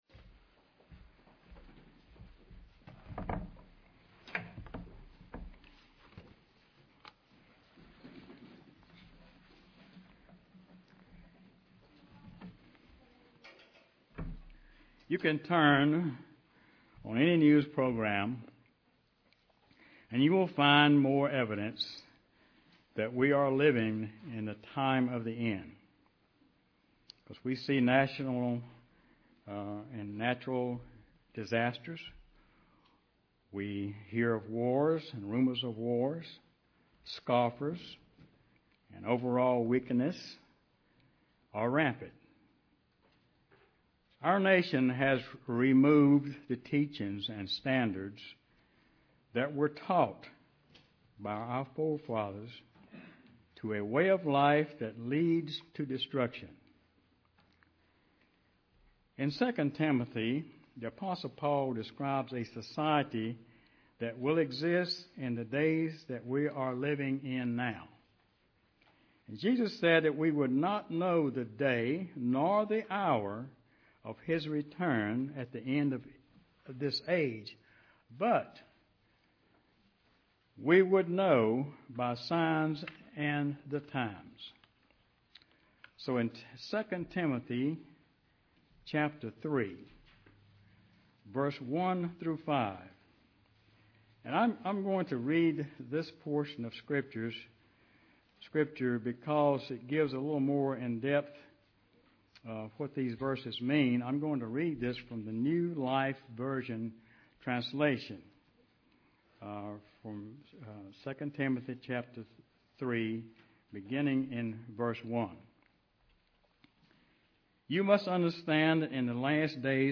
In this sermon the presenter discusses faithfulness and encourages us to answer this important question in Proverbs 20:6.
Given in Greensboro, NC